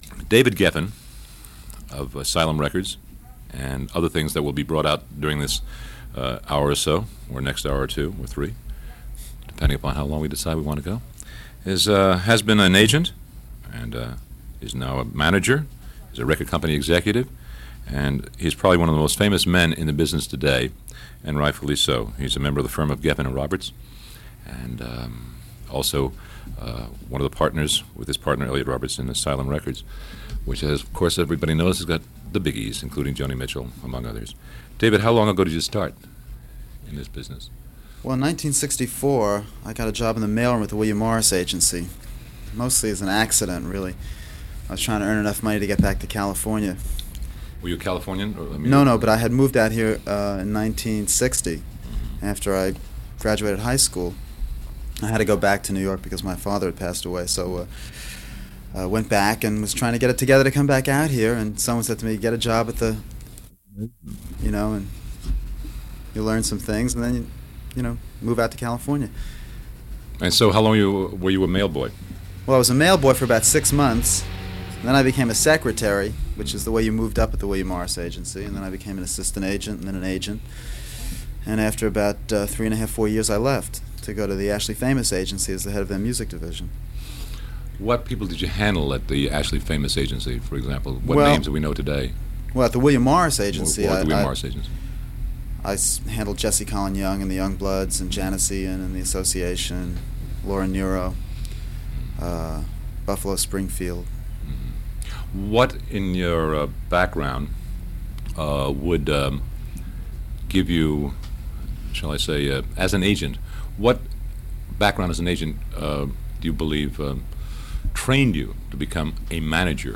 A Word Or Two With David Geffen - 1973 - David Geffen is interviewed by Mitchel Reed in this 45 minute talk - Past Daily Weekend Pop Chronicles
In my seemingly endless task of digitizing history; of the news kind and of the Popular Culture kind, I ran across this interview with the legendary David Geffen, a little over a year after forming Asylum Records and on the eve of breaking several new careers, including Tom Waits. He is interviewed by equally legendary B. Mitchel Reed at KMET.
BMR-Geffen-interview-1973.mp3